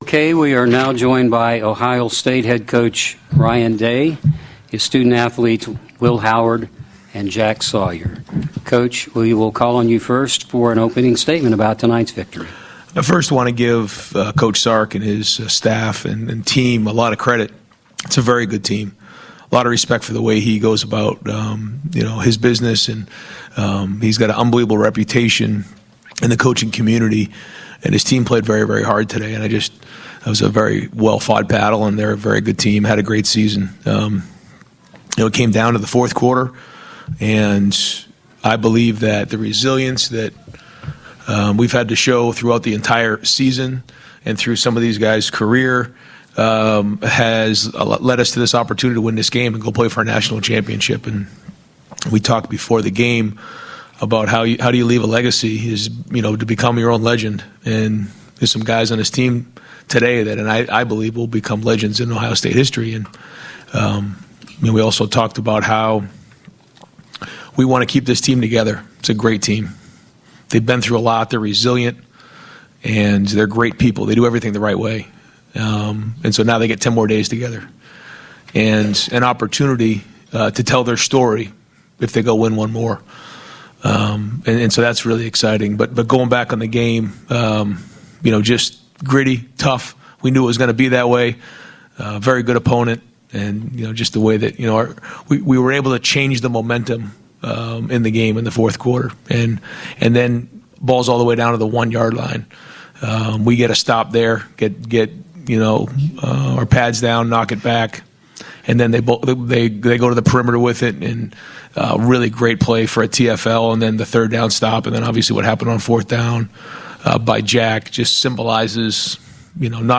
Will Howard OSU QB